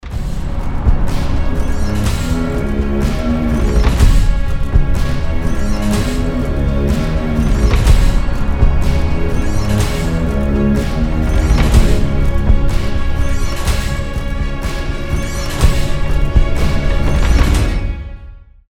• Качество: 320, Stereo
без слов
из фильмов
оркестр
эпичные
тревога